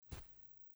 在薄薄的积雪中轻轻的行走脚步单声－左声道－YS070525.mp3
通用动作/01人物/01移动状态/02雪地/在薄薄的积雪中轻轻的行走脚步单声－左声道－YS070525.mp3